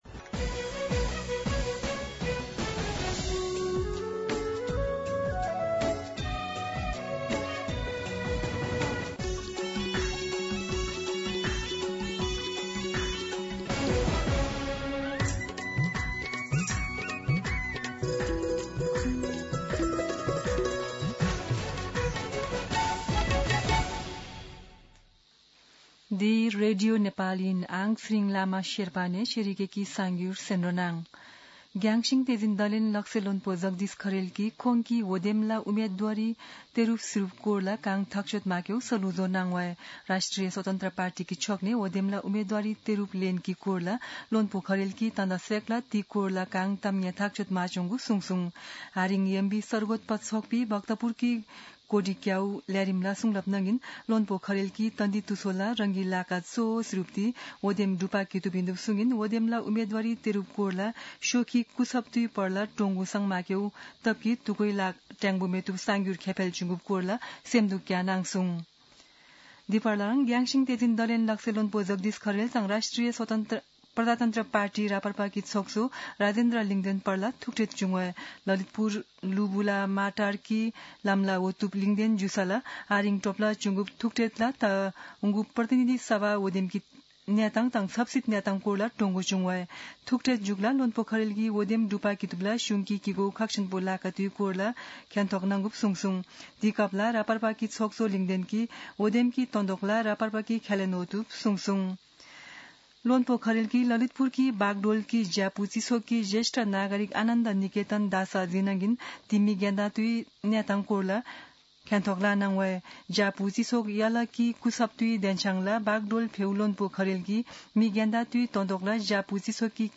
शेर्पा भाषाको समाचार : २३ पुष , २०८२
Sherpa-News.mp3